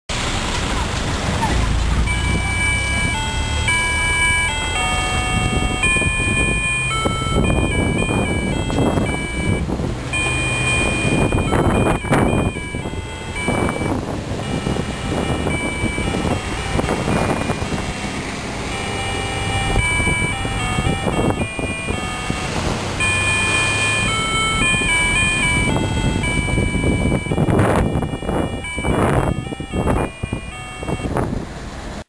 やはり１曲流れてくれません・・・。